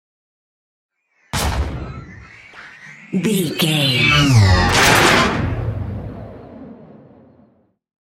Double hit with whoosh large sci fi
Sound Effects
Atonal
dark
futuristic
intense
woosh to hit